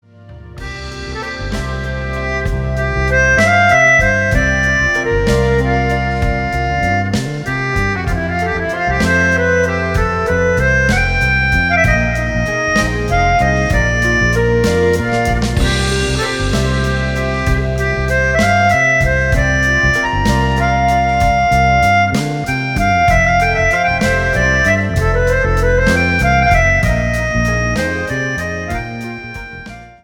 サウンドをさらにパワーアップさせている。